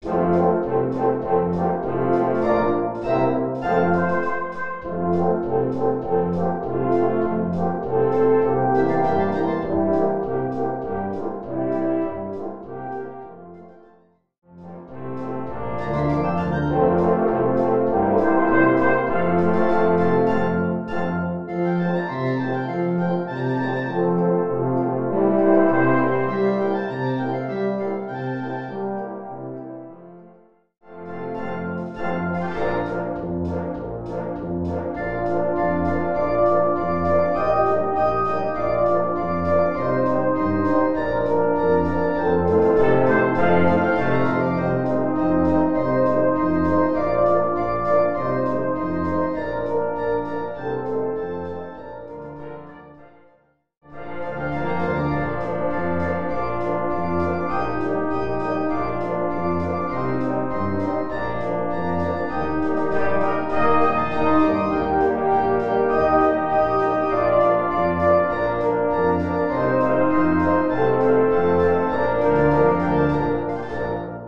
gut klingende Polka mit Klarinettensolo im 2. Teil